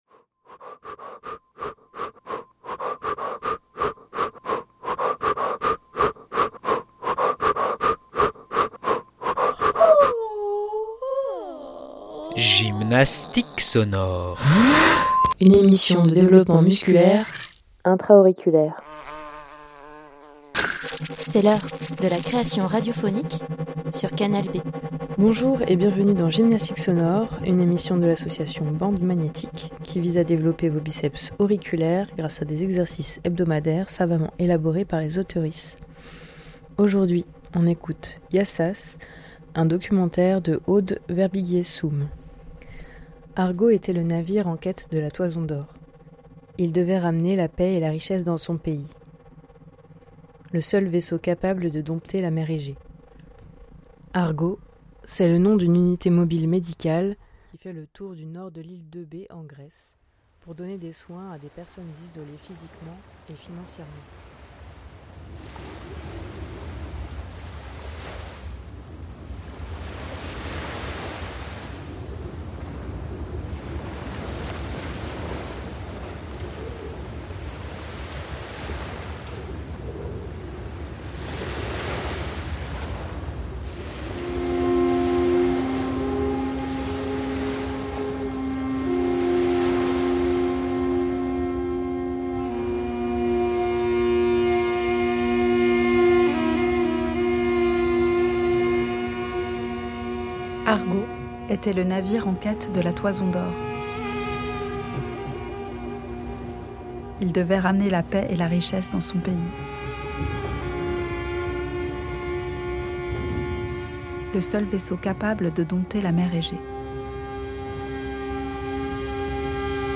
Également en écoute sur la revue sonore Le grain des choses .